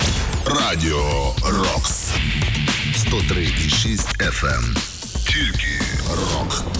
147 Кб 24.01.2010 17:47 Джингл для